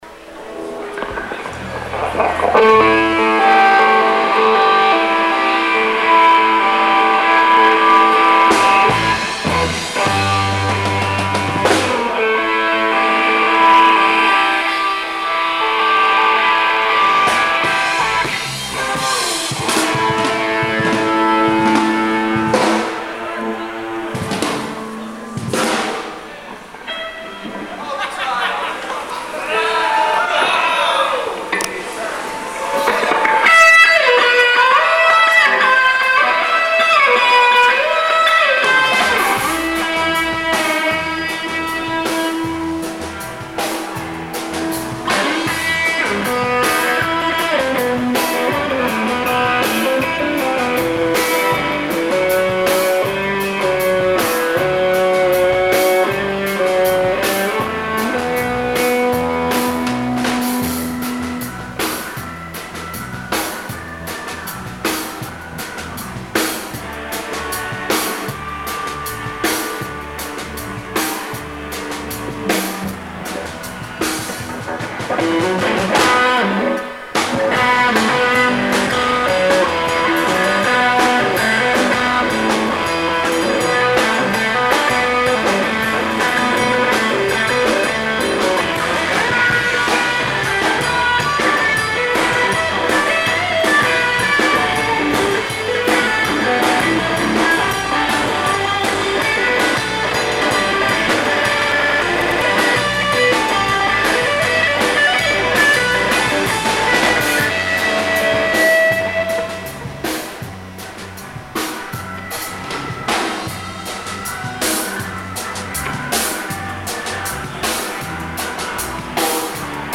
Monster Pete and the Chiefs: Live at Twister's Rock Climbing Gym